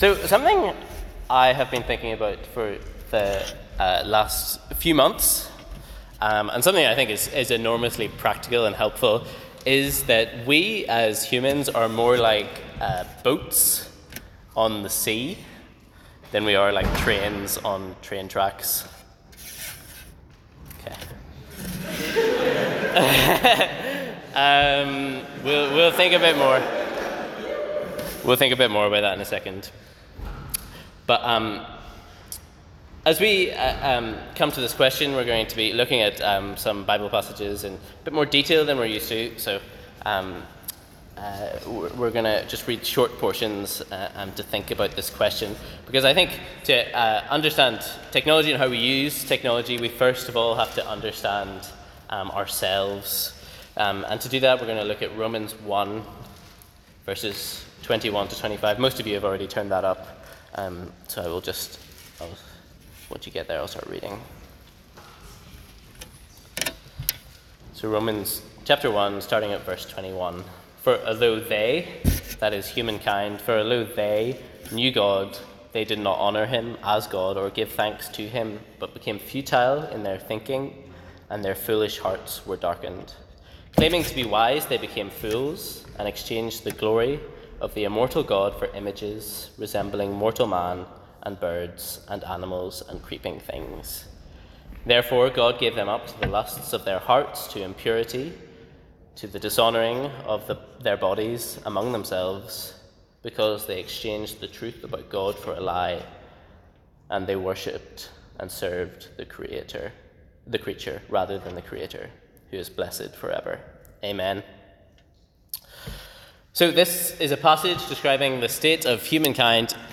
Apologies! Some audio from this recording was lost during feedback from the floor, so it may be hard to follow in places.